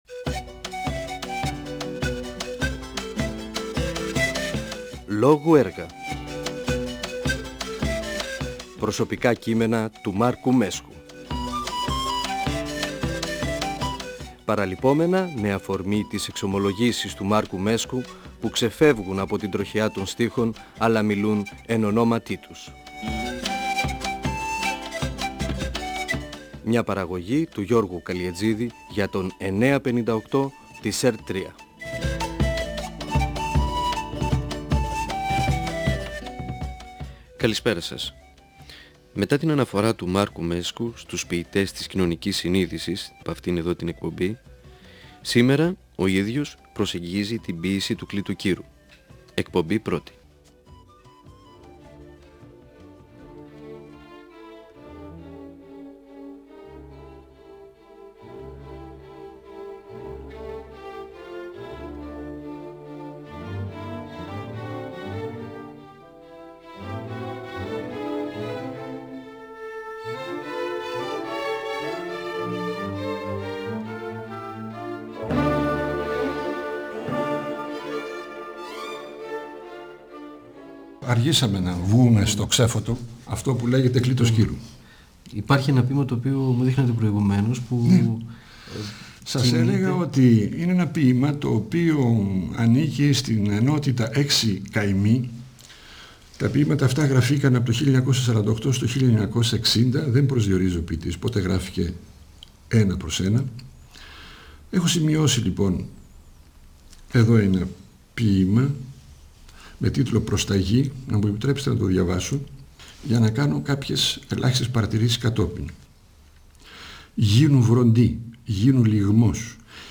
Ο ποιητής και δοκιμιογράφος Μάρκος Μέσκος (1935-2019) μιλά για τους ποιητές τής «κοινωνικής συνείδησης» και για τον ποιητή Κλείτο Κύρου (εκπομπή 1η).
Αφορμή για τη συζήτηση με τον Μάρκο Μέσκο στάθηκε το βιβλίο του «Προσωπικά κείμενα» (εκδ. Νεφέλη, 2000).ΦΩΝΕΣ ΑΡΧΕΙΟΥ του 958fm της ΕΡΤ3.